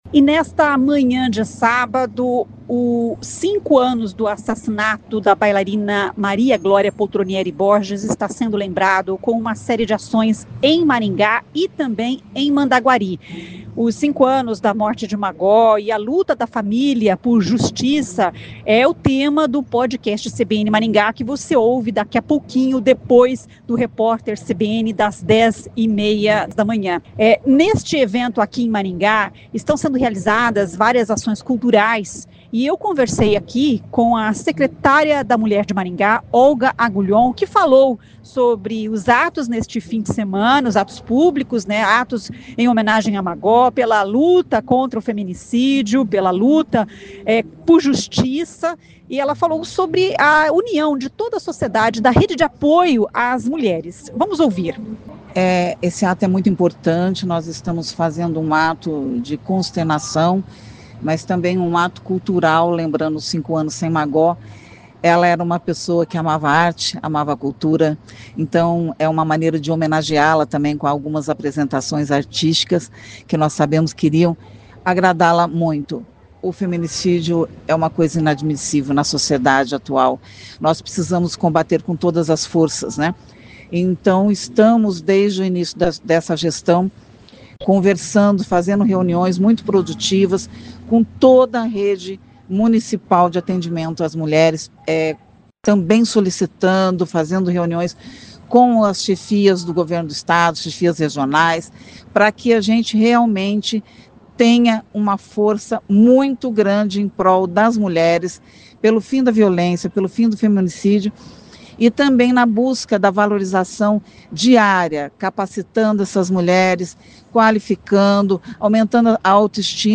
A secretária da Mulher de Maringá Olga Agulhon que falou sobre os atos neste fim de semana, a luta contra o feminicídio e a união de toda a sociedade na rede de apoio às mulheres.